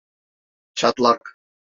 Pronounced as (IPA) [tʃɑtˈɫɑk]